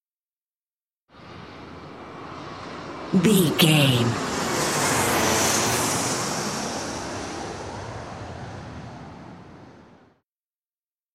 Airplane passby
Sound Effects